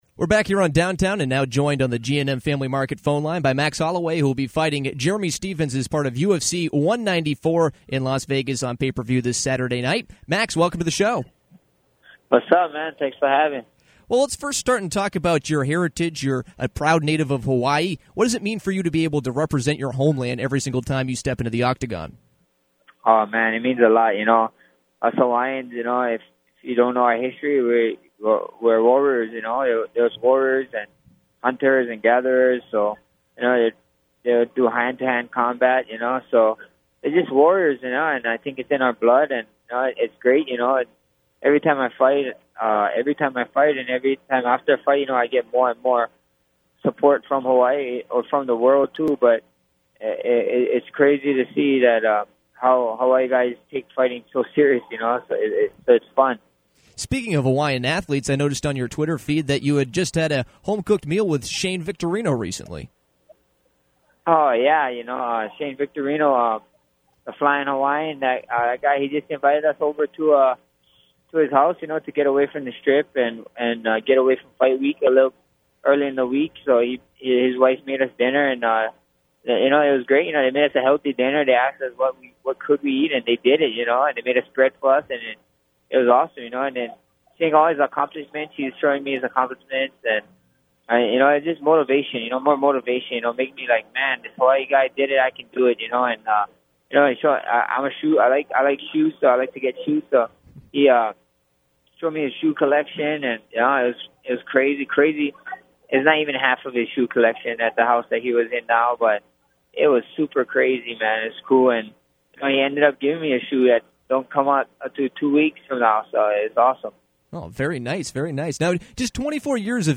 UFC featherweight fighter Max Holloway came on Downtown to preview his big match-up with Jeremy Stephens at the UFC 194 pay-per-view. Holloway spoke about representing his home state of Hawaii and told a story of how he recently spent time with former Red Sox Shane Victorino. He talked about how he looks towards the future in his MMA career instead of his past success, but did say that his loss to Conor McGregor in 2013 was a turning point in his profession.